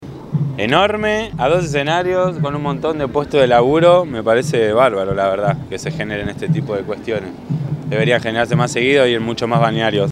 Fiesta de la Cerveza Artesanal en Parque del Plata
artista_circense.mp3